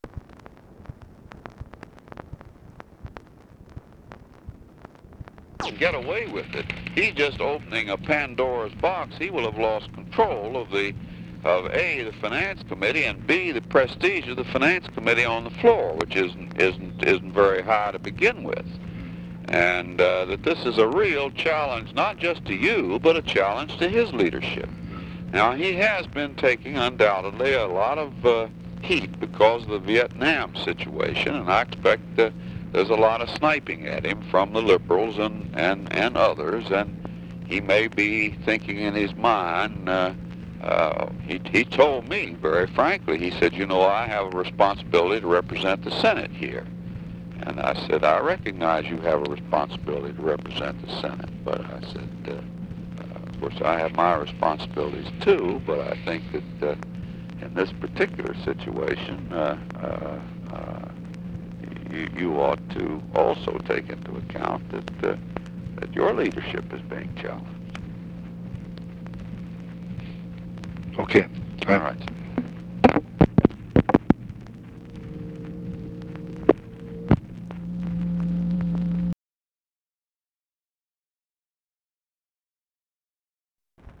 Conversation with HENRY FOWLER, March 10, 1966
Secret White House Tapes